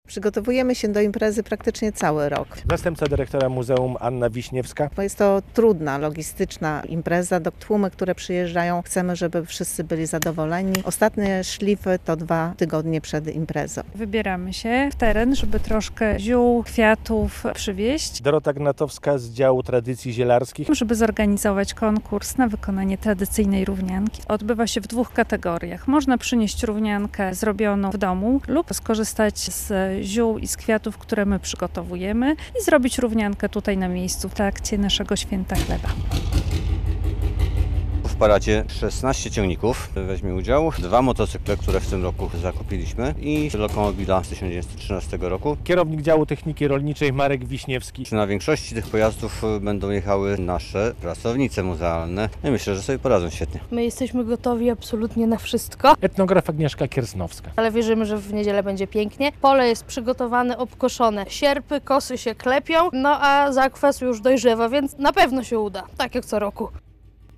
Przygotowania do Święta Chleba - relacja